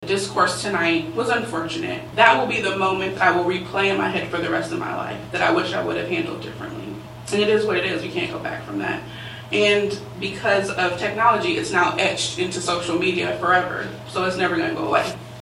Tensions rose between the public during Wednesday’s USD 383 Manhattan-Ogden school board meeting.
Coleman also took time during board comments to respond to the incident.